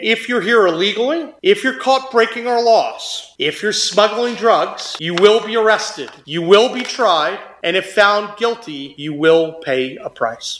During a recent press conference, Governor Patrick Morrisey reaffirmed that West Virginia is a law and order state…